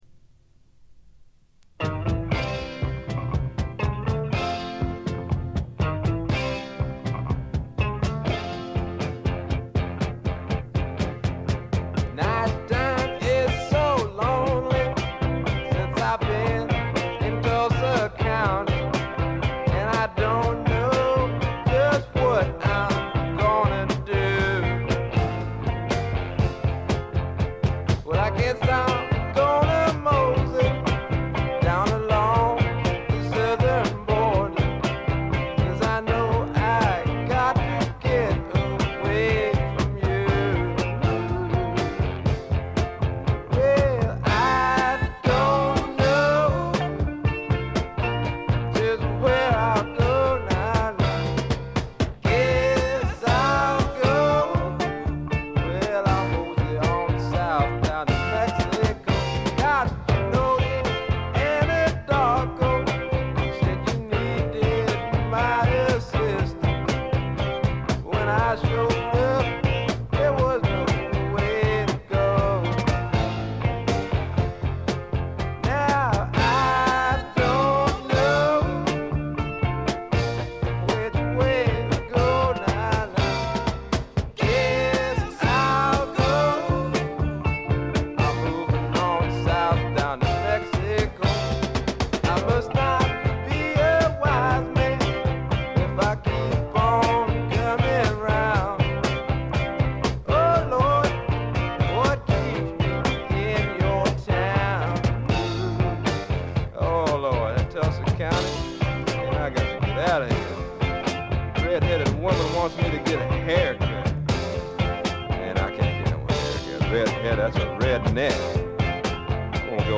ノイズ感無し。
まさしくスワンプロックの真骨頂。
試聴曲は現品からの取り込み音源です。